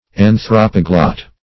Search Result for " anthropoglot" : The Collaborative International Dictionary of English v.0.48: Anthropoglot \An*throp"o*glot\, n. [Gr.